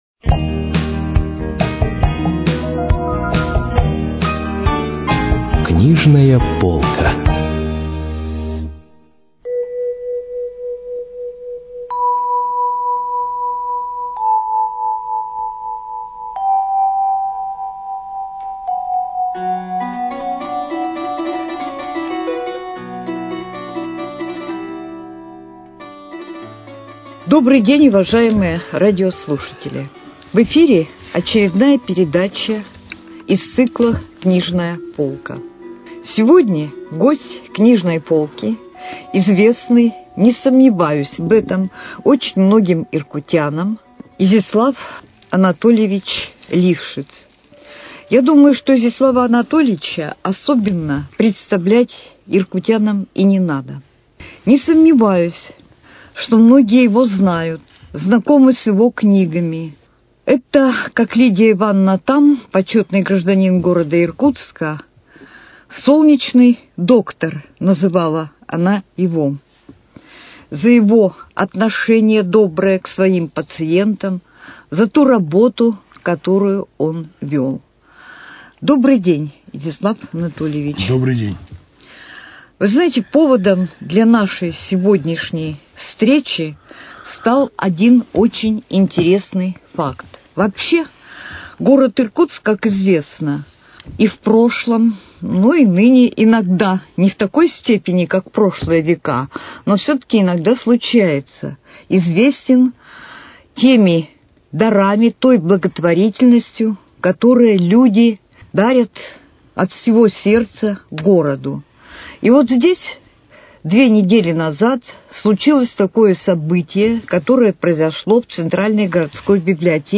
Передача из цикла «Книжная полка».